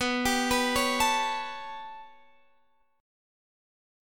Listen to B7sus2#5 strummed